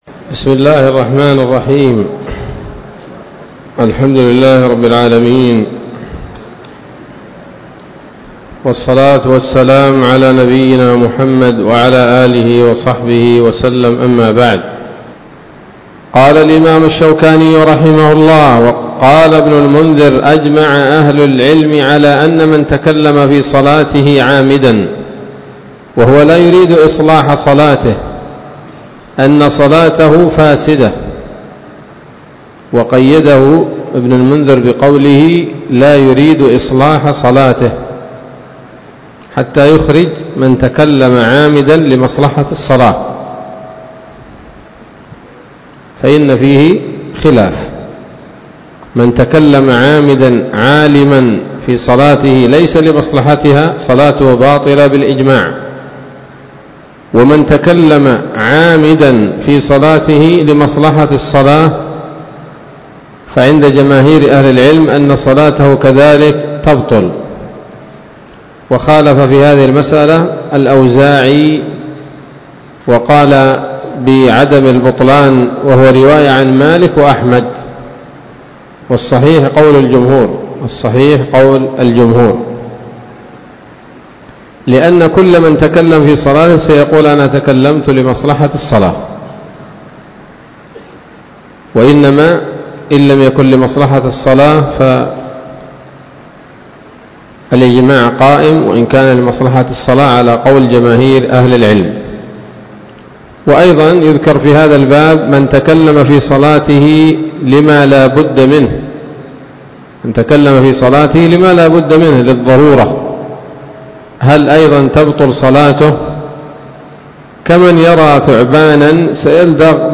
الدرس الثاني من أبواب ما يبطل الصلاة وما يكره ويباح فيها من نيل الأوطار